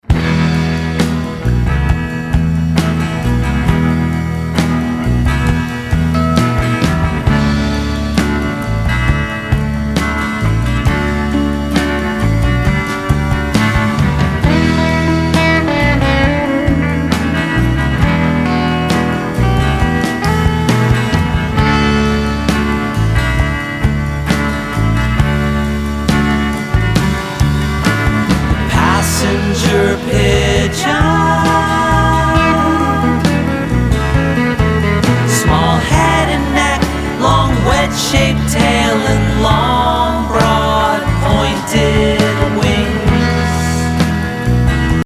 singable tunes and danceable rhythms